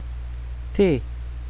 La pronuncia indicata qui è quella standard; va ricordato, però che la pronuncia aperta o chiusa delle e e delle o varia da regione a regione.
é = e chiusa; è e aperta
te_ch.wav